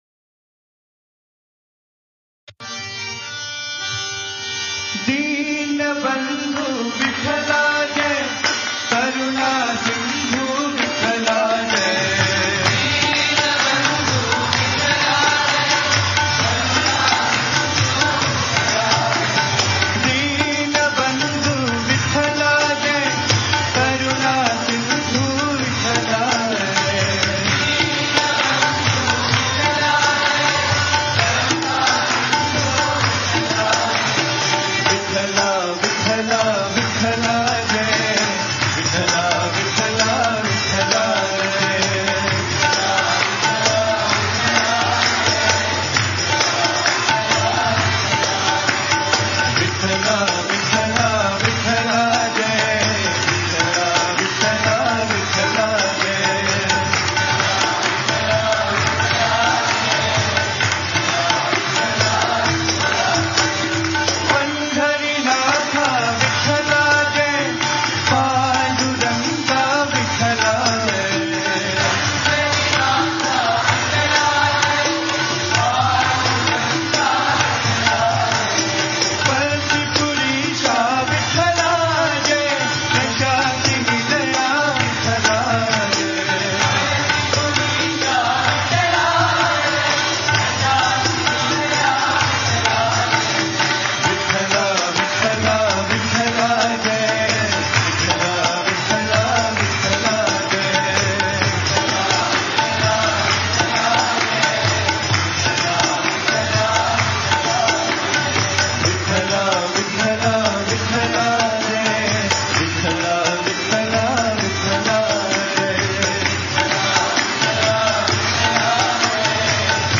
Home | Bhajan | Bhajans on various Deities | Vithala Bhajans | 03 Deena Bandhu Vittahla Jai